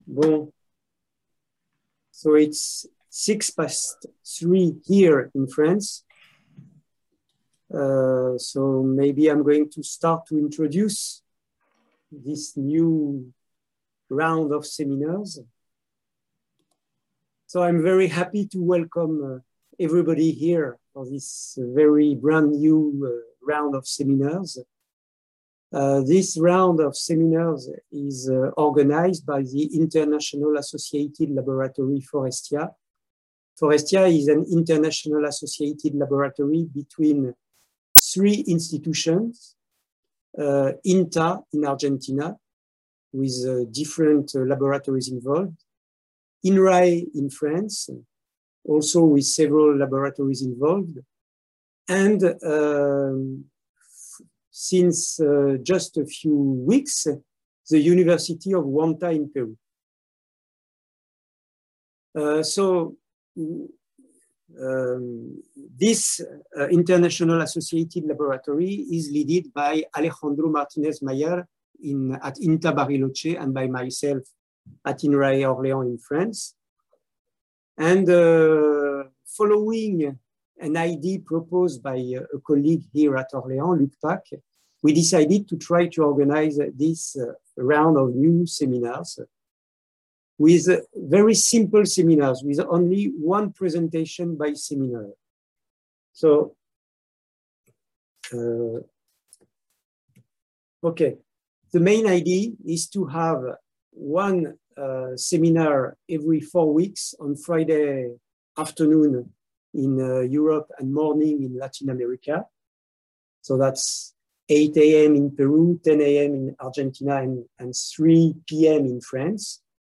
Round of FORESTIA web seminars SEMINAR # 1